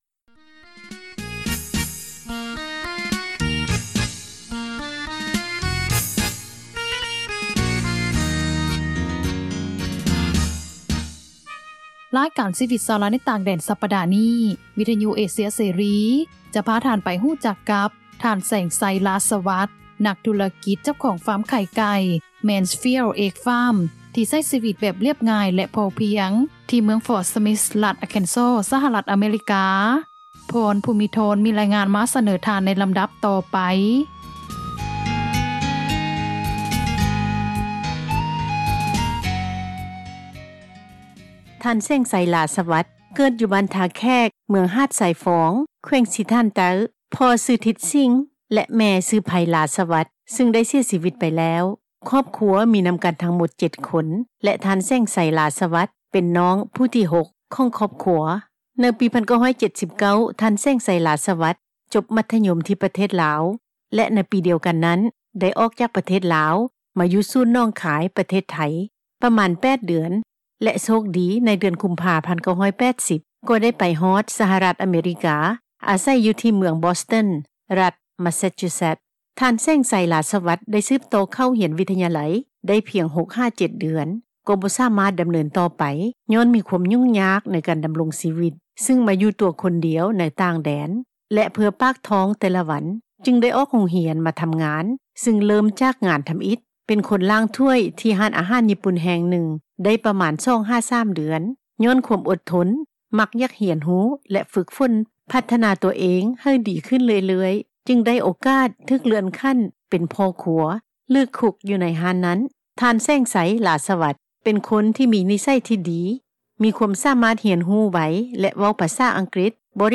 ສັມພາດ